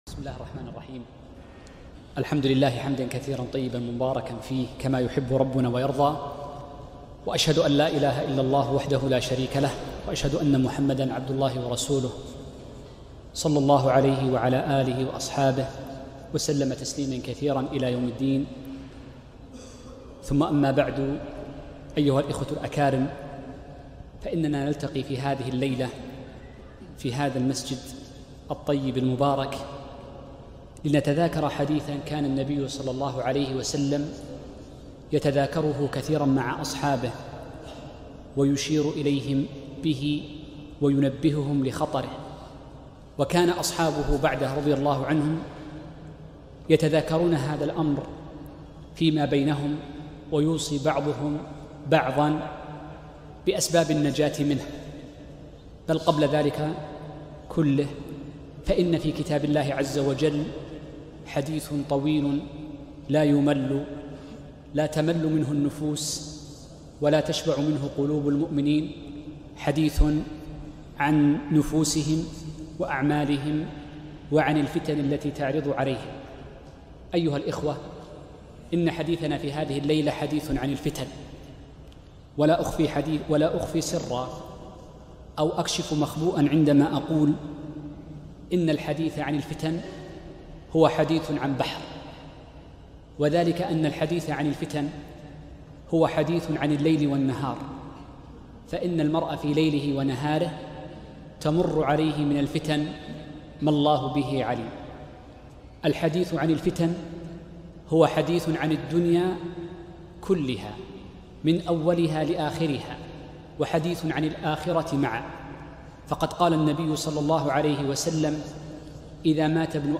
محاضرة - أسباب النجاة من الفتن